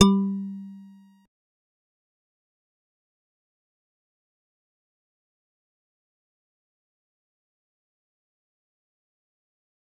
G_Musicbox-G3-pp.wav